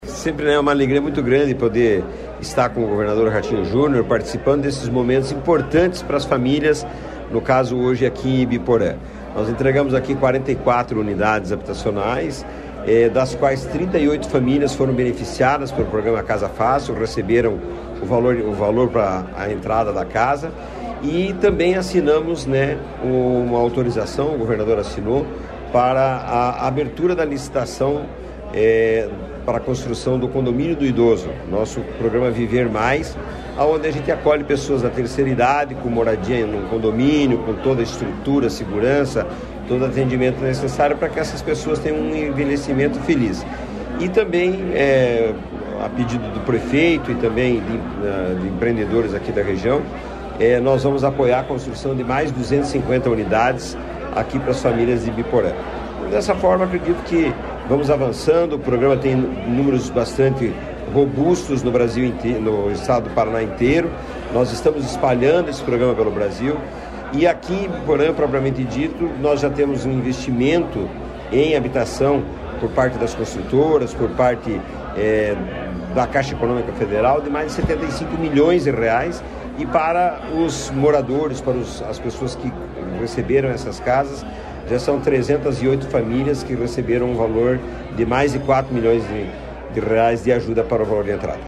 Sonora do presidente da Cohapar, Jorge Lange, sobre a entrega de moradias pelo Casa Fácil Paraná em Ibiporã